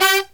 HIGH HIT04-R.wav